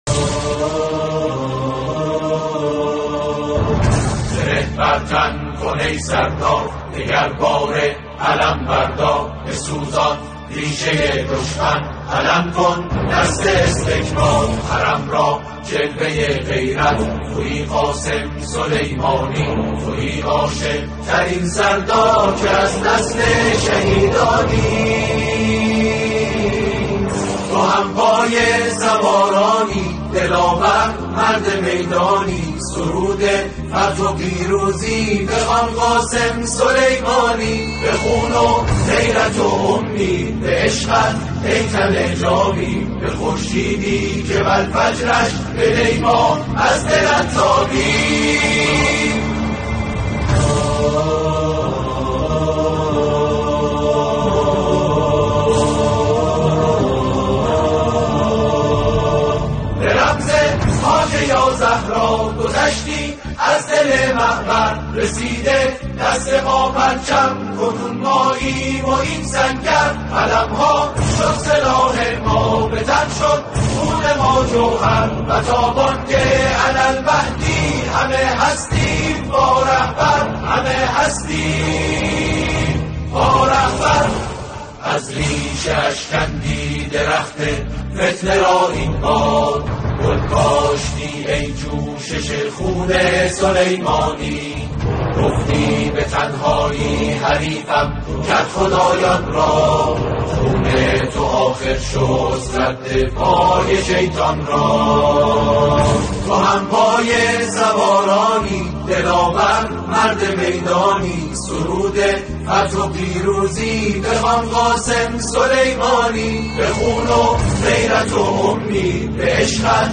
دانلود تواشیح